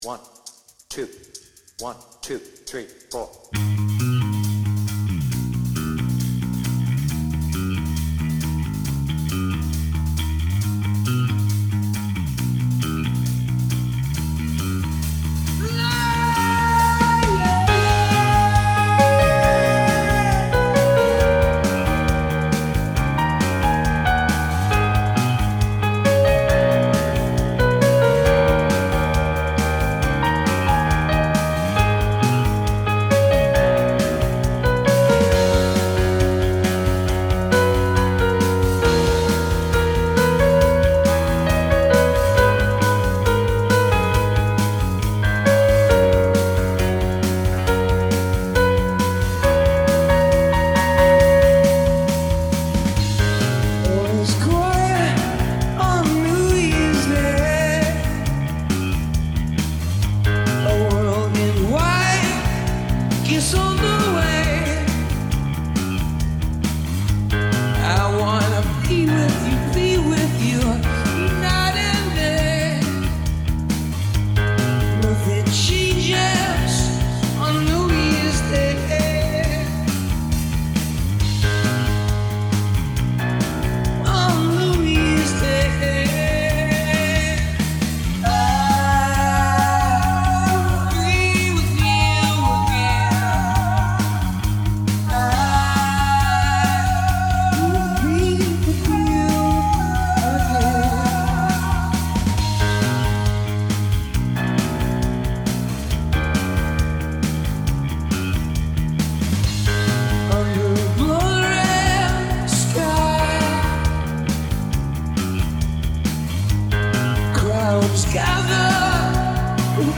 BPM : 136
Tuning : Eb
With vocals
Based on the Slane Castle version